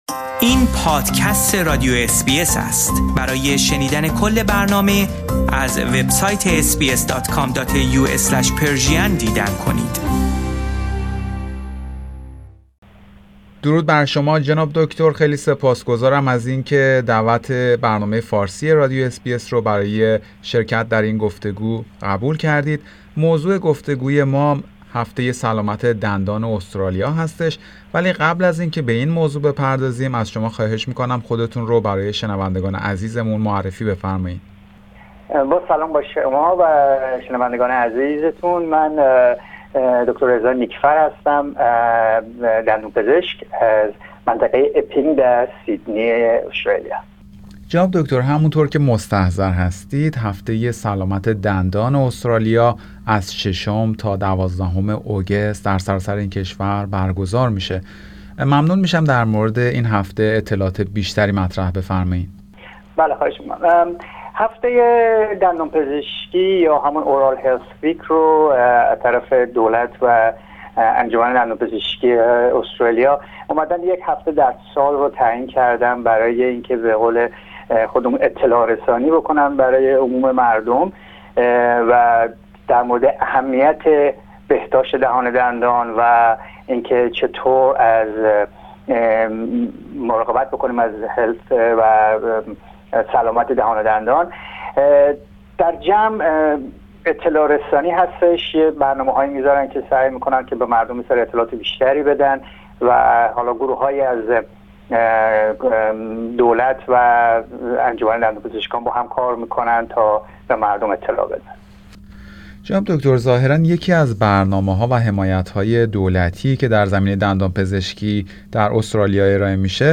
درباره همین موضوع گفتگویی داشتیم